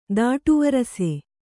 ♪ dāṭu varase